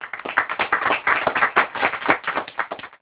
clapping_3sec.amr